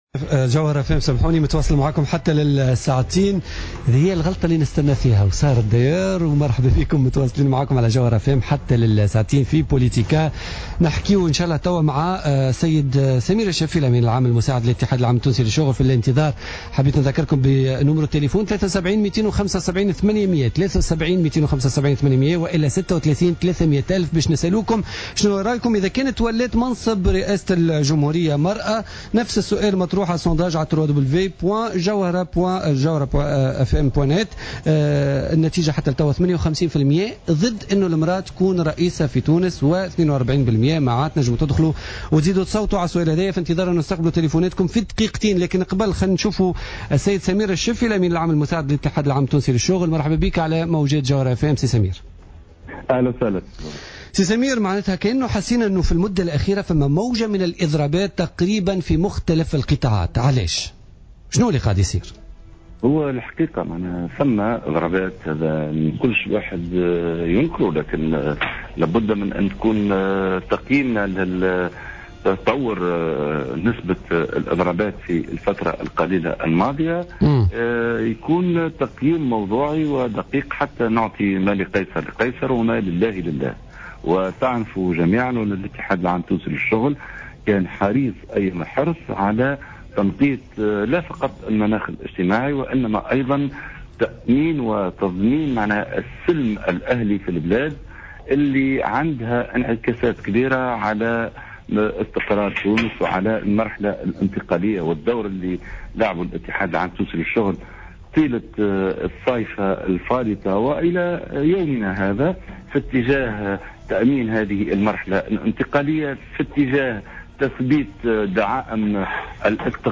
قال الأمين العام المساعد للاتحاد العام التونسي للشغل سمير الشفي" في مداخلة له في برنامج بوليتيكا اليوم الثلاثاء 23 سبتمبر 2014 أن الإتحاد العام التونسي للشغل كان حريصا على تنقية وتأمين السلم الأهلي في البلاد من خلال الدعوة الى الكف عن الإضرابات وهو ما انعكس على استقرار تونس وسهل المرحلة الإنتقالية.